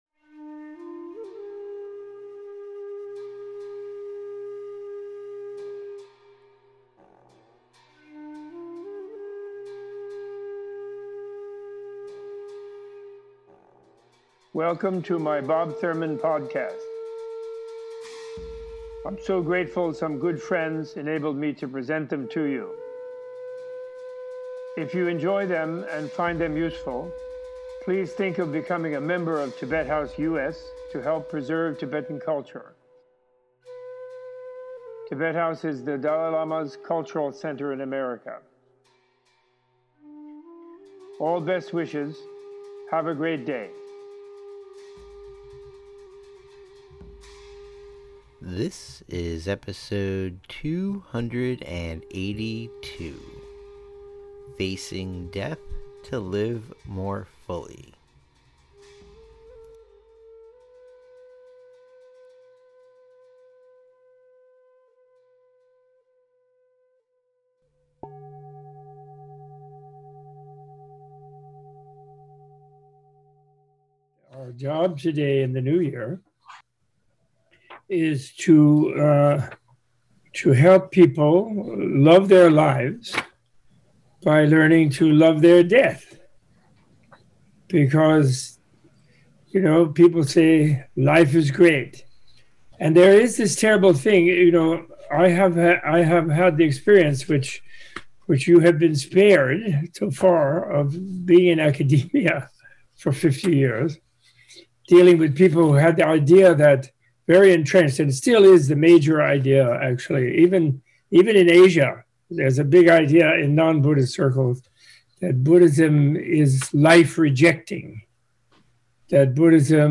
In this episode Robert Thurman gives a teaching on impermanence and the power of contemplating one’s mortality to inspire action, nurture gratitude, transform relationships, and prioritize what truly matters. Opening with a refutation of Buddhism as a life-rejecting philosophy, this podcast includes a short explanation of the Buddha’s “supreme contemplation” with guided meditations on embracing transience and death and an exploration of how considering death to be a good thing can help simplify life and re-set your priorities.